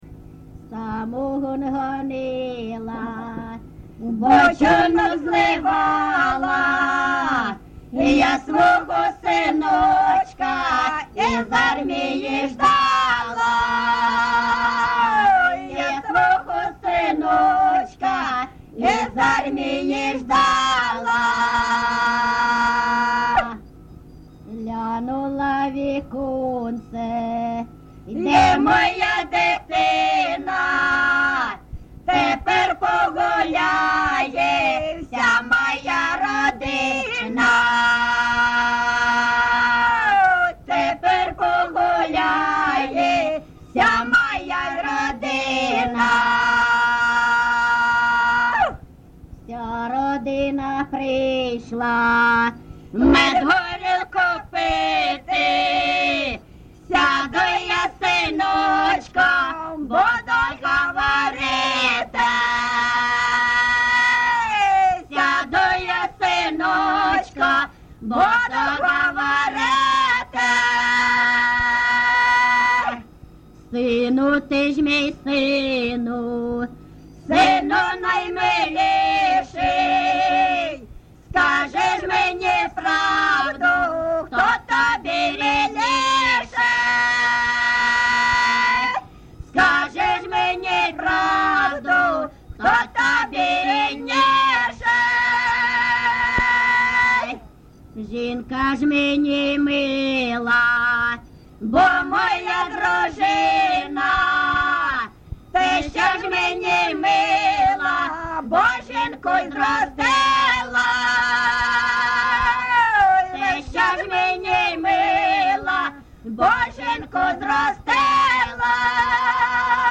ЖанрПісні з особистого та родинного життя
Місце записус. Свято-Покровське, Бахмутський район, Донецька обл., Україна, Слобожанщина